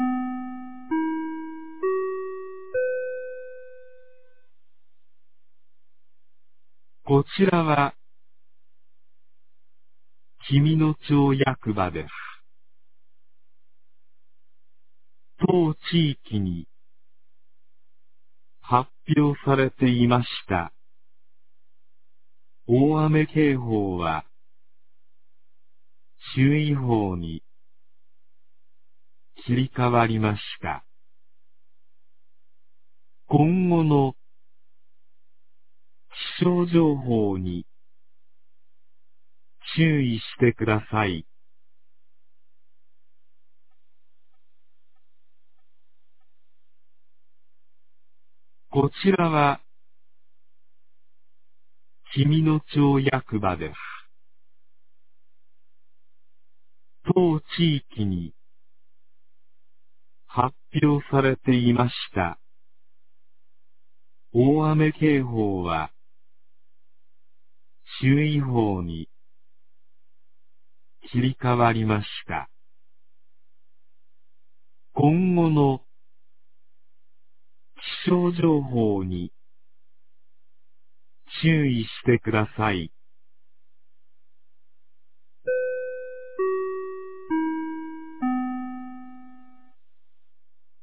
2023年06月03日 07時06分に、紀美野町より全地区へ放送がありました。